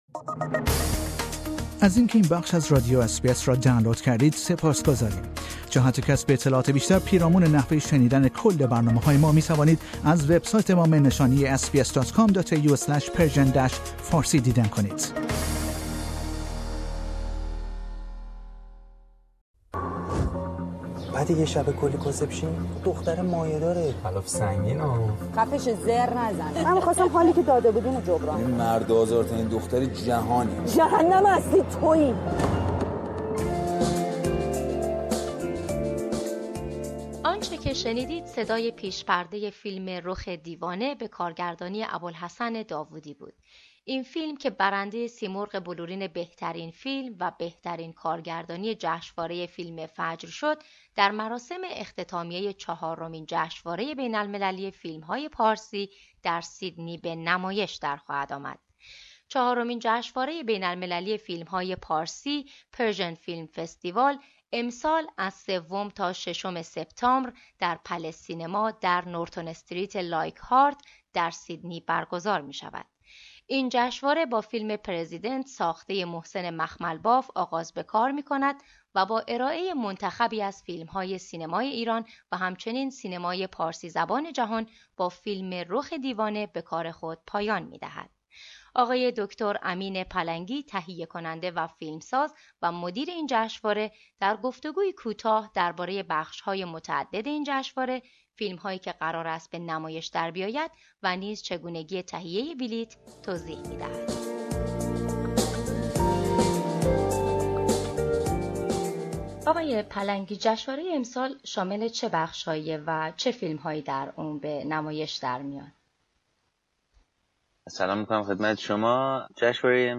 گفتگویی کوتاه